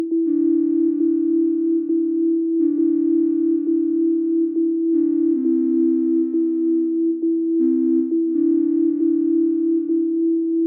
drone.mp3